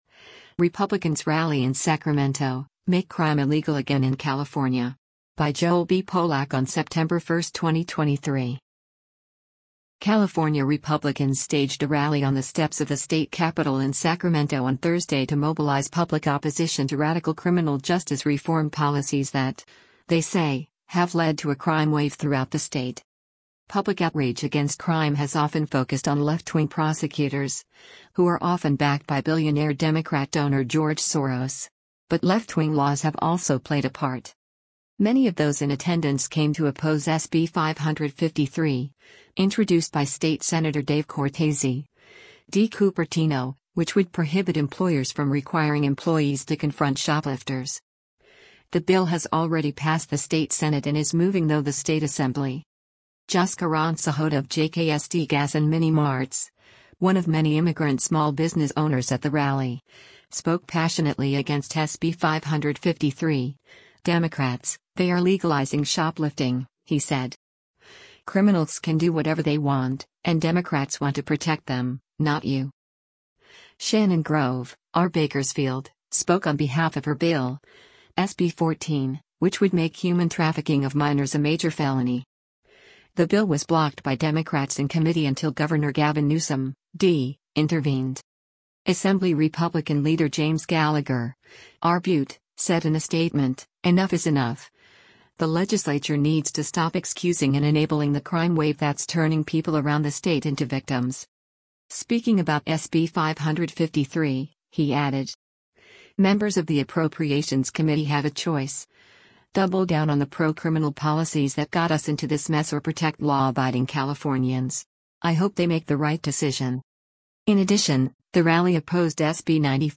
California Assembly Republicans hold a rally against crime at the State Capitol in Sacrame
Shopkeepers rally at the California State Capitol against crime and SB 553, which would bar employers from telling employees to confront shoplifters.